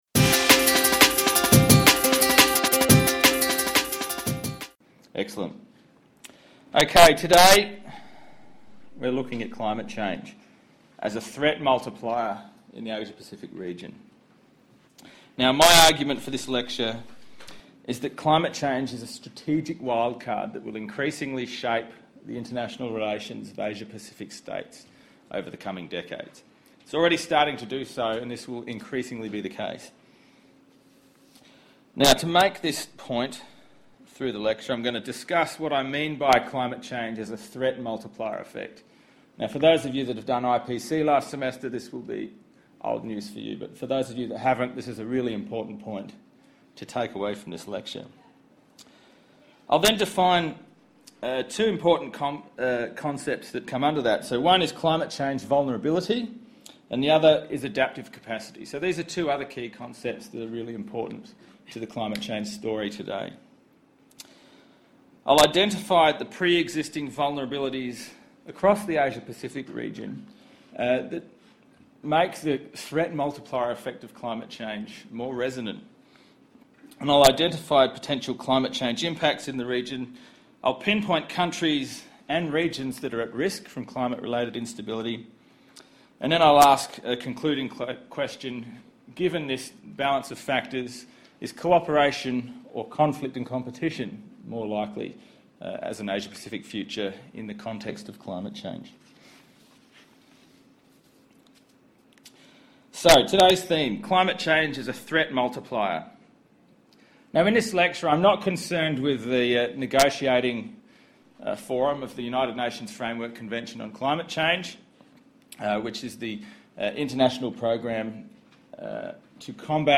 We can therefore think of climate change as a threat multiplier, capable of exacerbating pre-existing socio-economic and political weaknesses within states and in so doing, altering the dynamics of relationships between states. In this lecture, delivered to second and third year undergraduate students studying my subject Contemporary Politics in the Asia-Pacific Region, I explore the threat multiplier effect of climate change through the concepts of climate change vulnerability and adaptive capacity, in the context of the Asia-Pacific region.